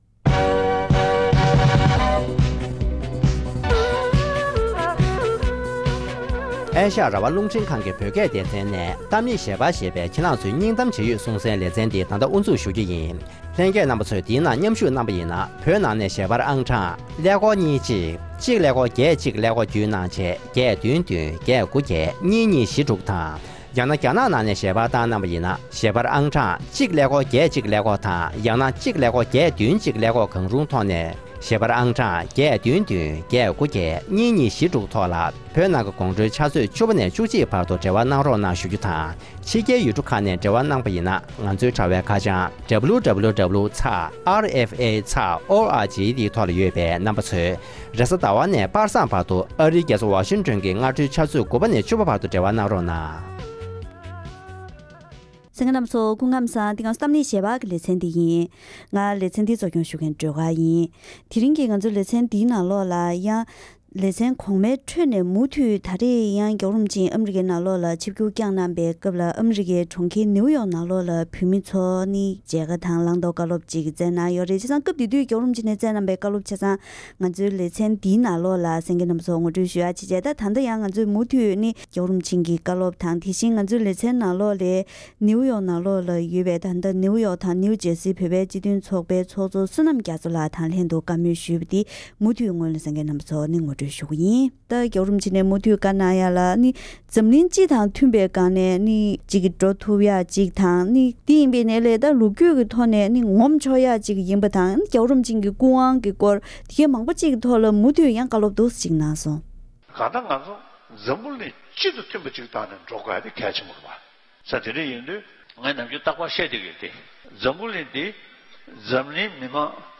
༄༅། །ཐེངས་འདིའི་གཏམ་གླེང་ཞལ་པར་ལེ་ཚན་འདིའི་ནང་། སྤྱི་ནོར་༸གོང་ས་༸སྐྱབས་མགོན་ཆེན་པོ་མཆོག་ནས་ཨ་རིའི་གྲོང་ཁྱེར་ནིའུ་ཡོག་ཏུ་བོད་མི་ལྔ་སྟོང་ལྷག་ཙམ་ལ་དོལ་རྒྱལ་དང་འབྲེལ་བའི་སྐོར་དང་། དེ་བཞིན་བོད་མི་ཚོས་ཡ་རབས་སྤྱོད་བཟང་དགོས་པའི་སྐོར་ལ་དམིགས་བསལ་བླང་དོར་བཀའ་སློབ་གནང་བ་ཁག་གི་ཐོག་འབྲེལ་ཡོད་དང་ལྷན་བཀའ་མོལ་ཞུས་པ་ཞིག་གསན་རོགས་གནང་།།